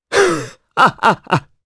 Dimael-Vox_Happy3_jp.wav